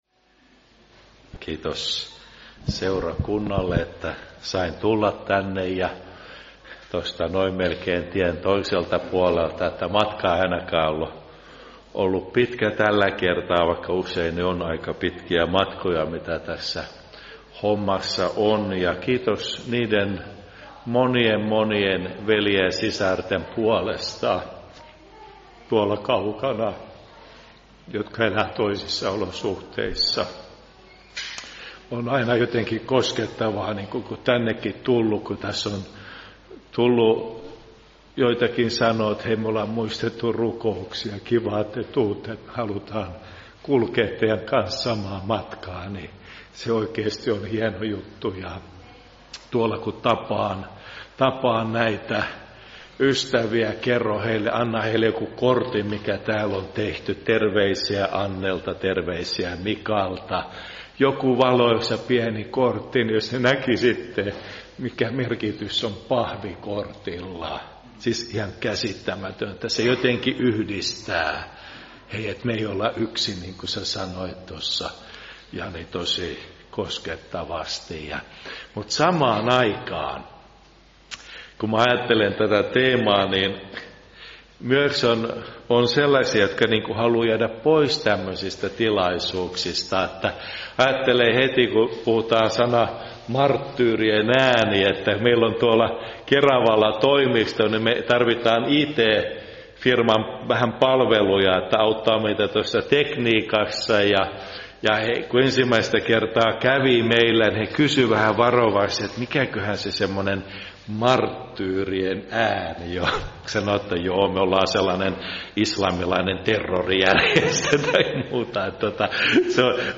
Ehtoolliskokous